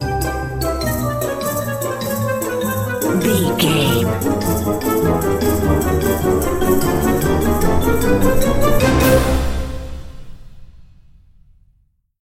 Uplifting
Lydian
flute
oboe
strings
orchestra
cello
double bass
percussion
silly
circus
goofy
comical
cheerful
perky
Light hearted
quirky